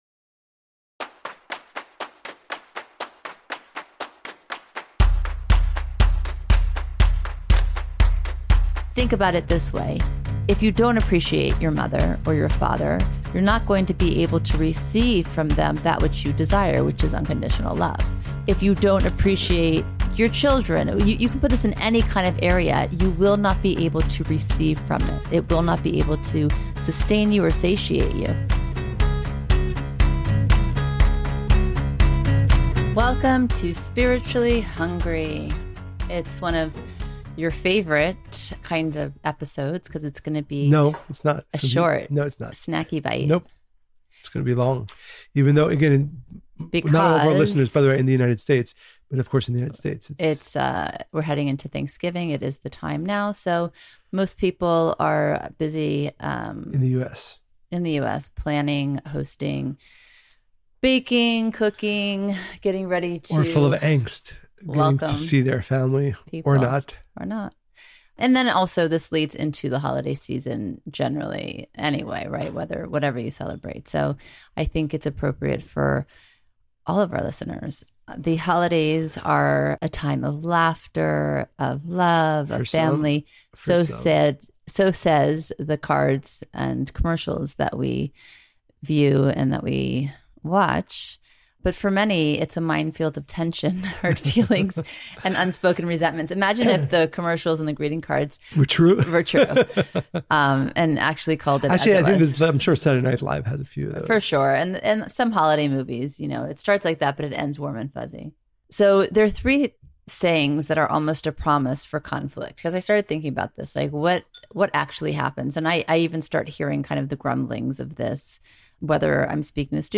Even the most strained and difficult relationships can be a source of meaningful growth. Join us for a conversation that can help each of us enter the holiday season with appreciation.